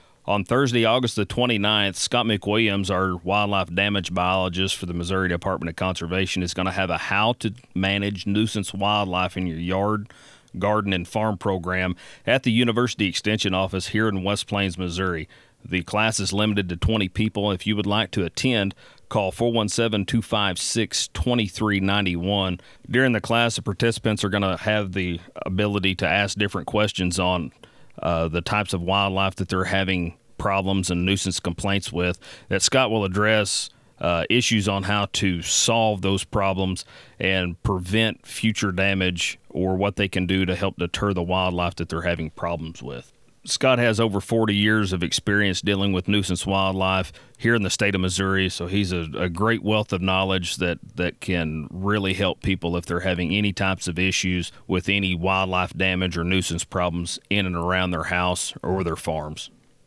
Conservation agent